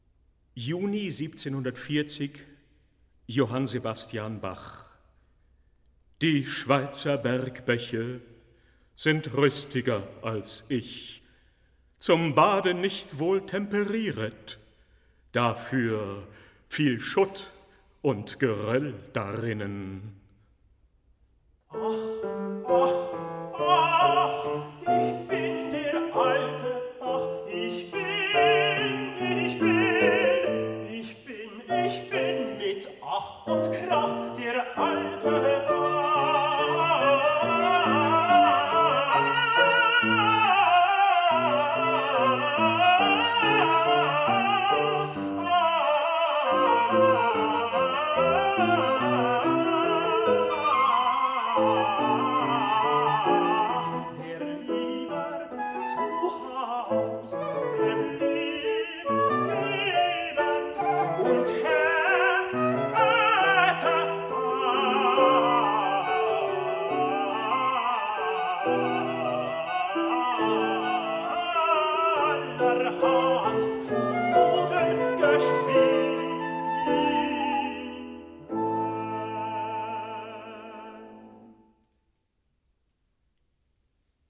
Albert Moeschinger: Johann Sebastian Bach, 1740 (Tenor, flute, piano)